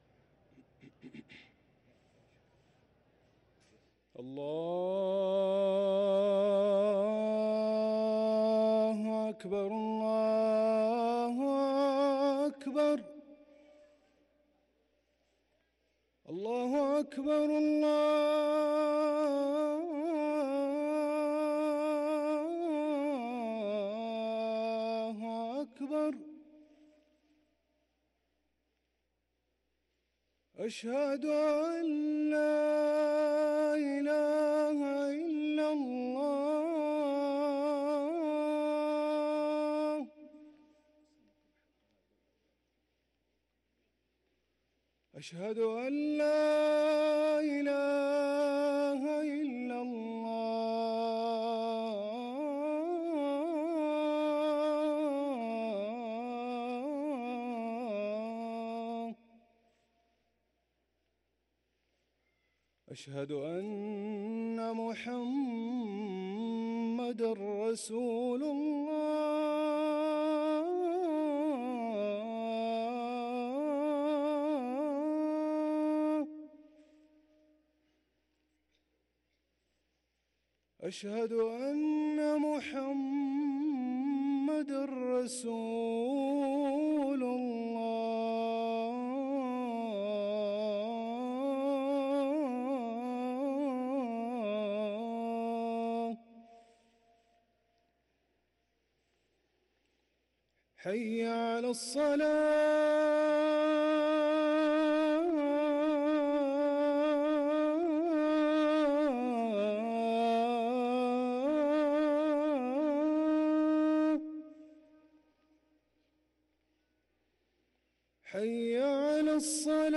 أذان المغرب للمؤذن هاشم السقاف الخميس 19 جمادى الآخرة 1444هـ > ١٤٤٤ 🕋 > ركن الأذان 🕋 > المزيد - تلاوات الحرمين